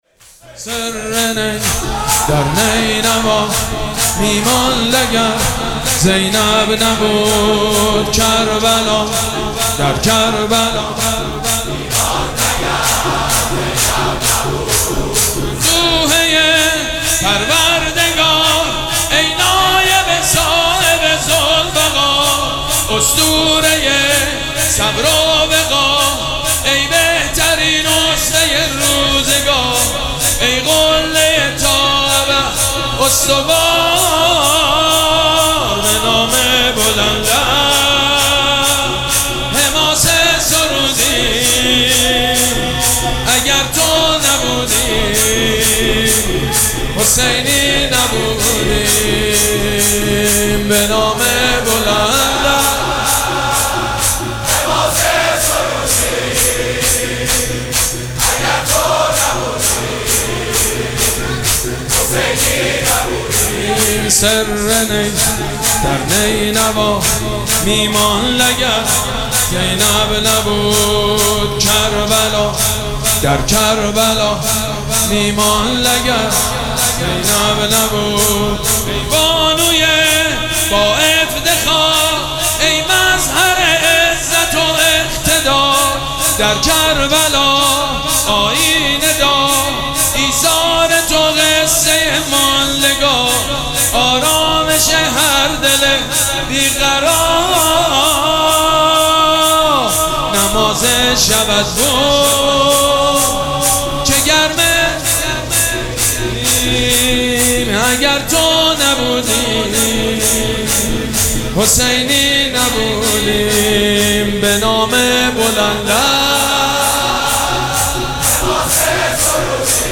مراسم عزاداری شب چهارم محرم الحرام ۱۴۴۷
شور
حاج سید مجید بنی فاطمه